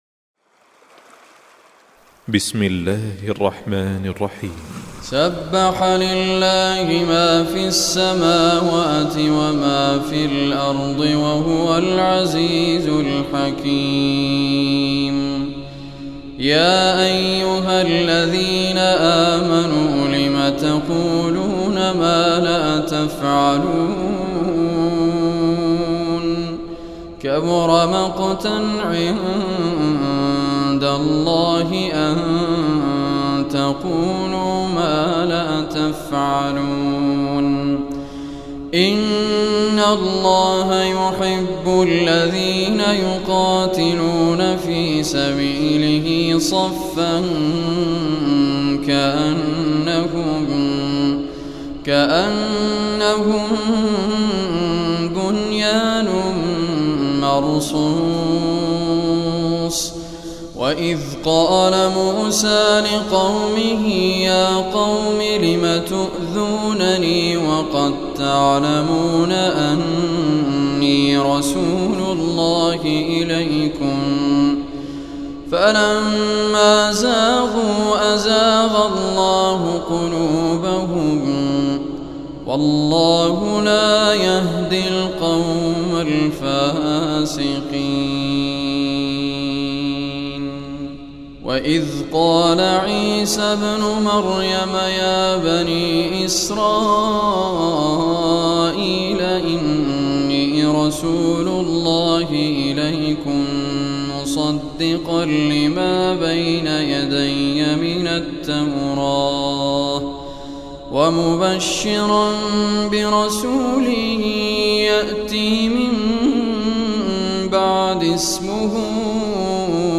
Surah As-Saff MP3 Recitation by Sheikh Raad Kurdi
Surah As-Saff, listen or play online mp3 tilawat / recitation in Arabic in the beautiful voice of Sheikh Raad al Kurdi.